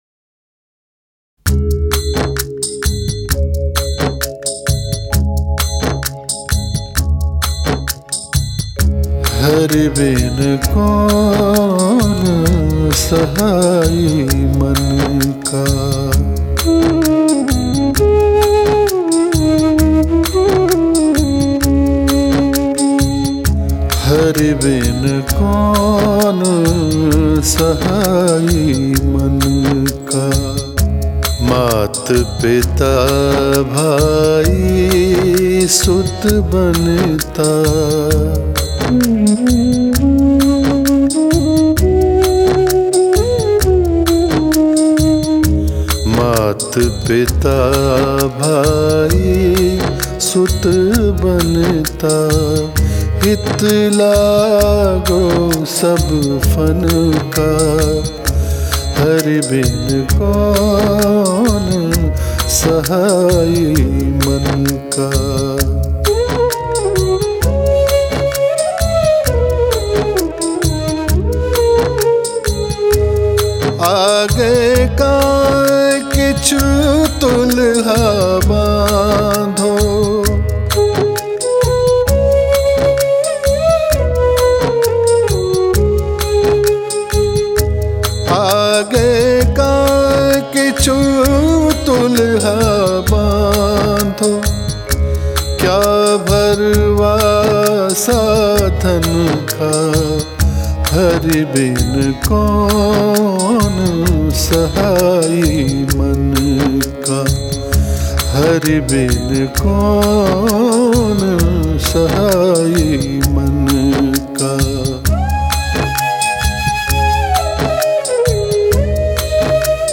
Kalam/Shabad